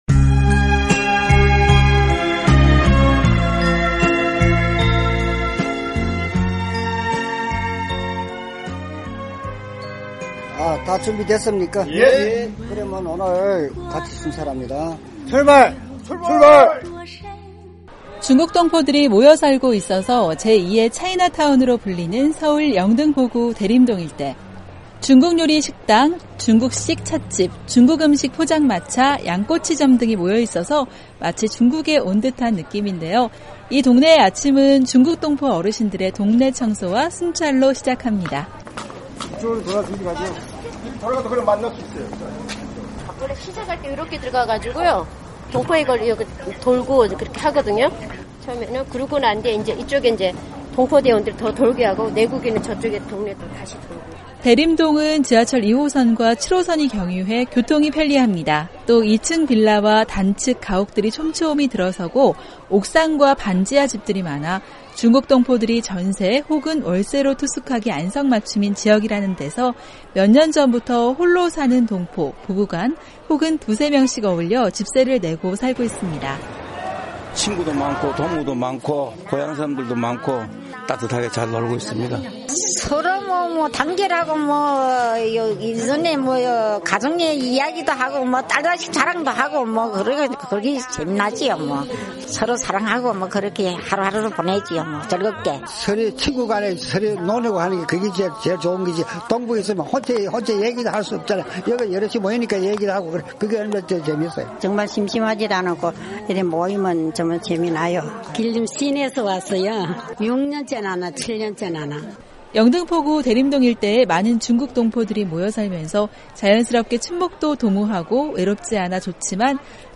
한국사회의 이모저모를 전해드리는 ‘안녕하세요 서울입니다 순서. 중국 출신 한국인이 많이 모여 사는 서울 영등포구 대림동 일대에서는 그들만의 자율 방범 대원가 활동하고 있습니다. 서울에서